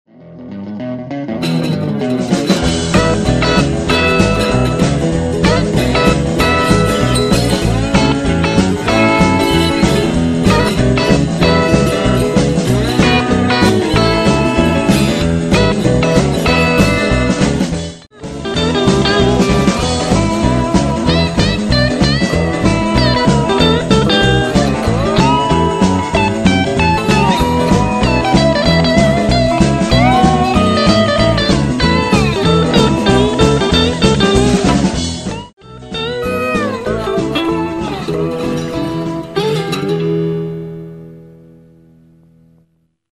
Singlecones sind etwas schärfer und rauher.
Und was auf die Ohren gibt es auch noch, wenn Ihr mögt, ich habe hier ein kleines Sample, bei dem auf der linken Seite meine alte Framus und auf der rechten Seite die Tricone zu hören ist, so wie auf dem Bild hier:
Framus und Tricone - Soundschnipsel
sample_framus_tricone.mp3